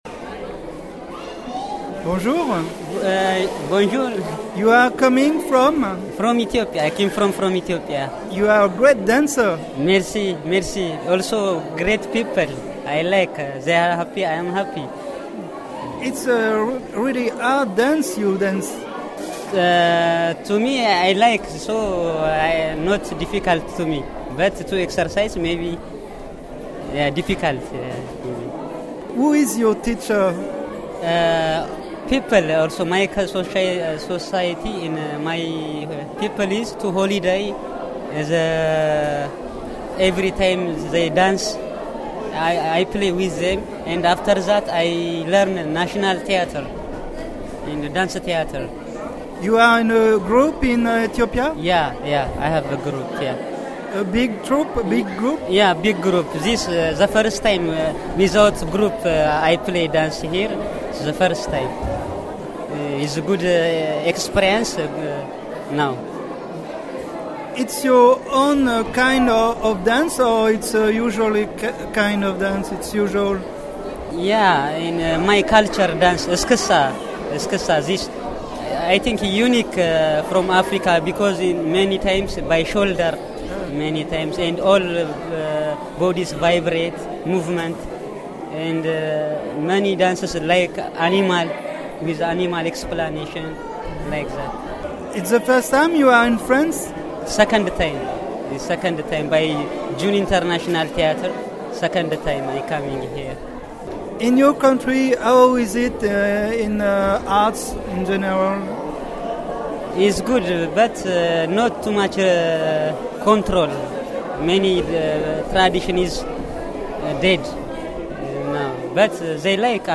Reportage sur "Résidents du Monde".